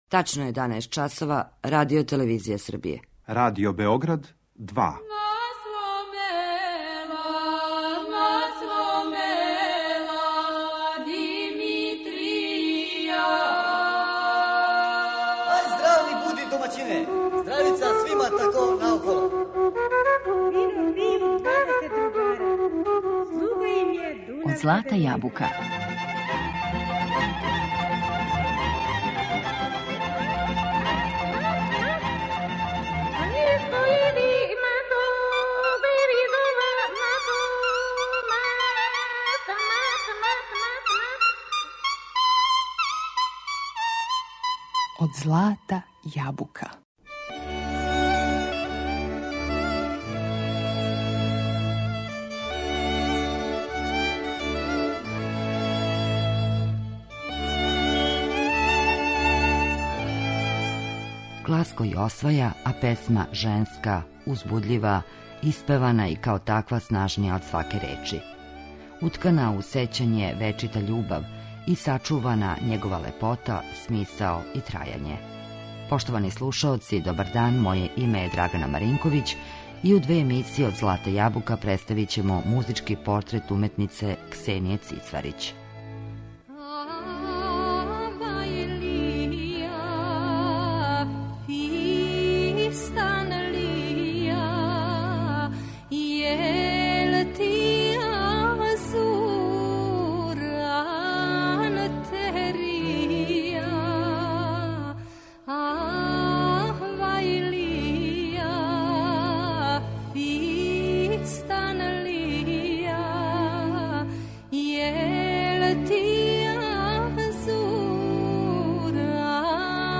Нараштајима је завештала предивне мелодије свога завичаја, гласом јединственим у нашем народном појању.